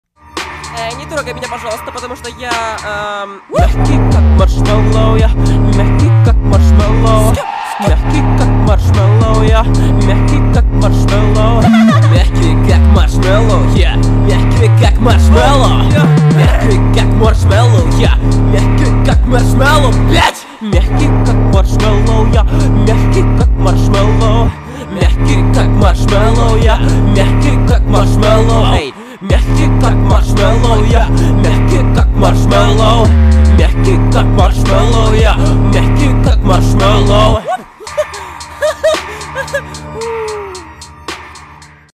• Качество: 192, Stereo
веселые
мощные басы